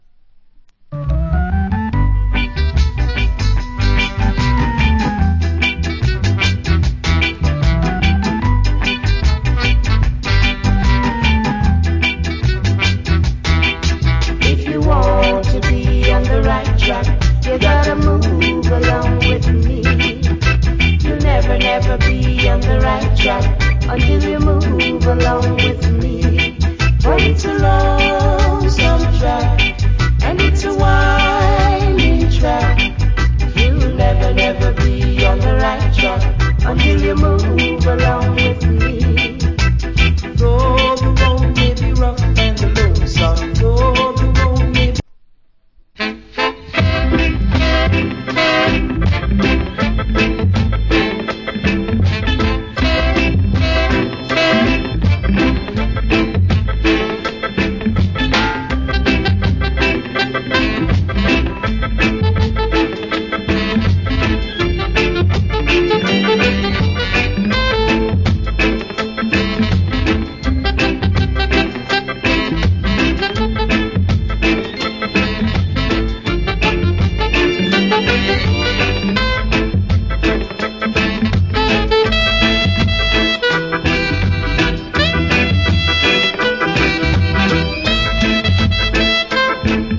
Wicked Duet Early Reggae Vocal.